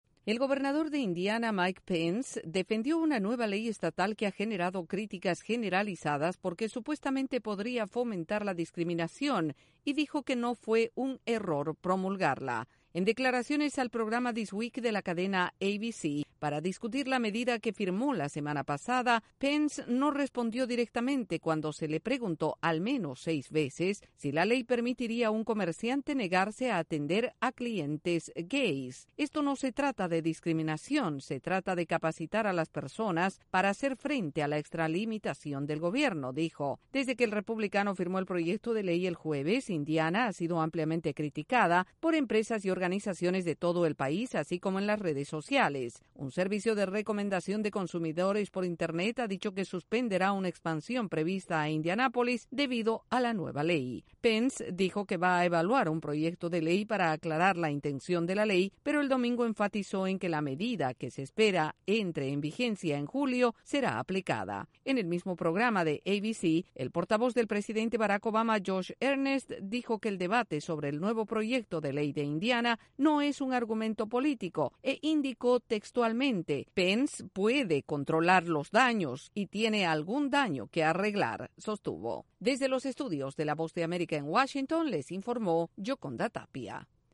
El gobernador de Indiana defiende una controvertida ley en Indiana que provocaría discriminación. El informe desde la Voz de América en Washington DC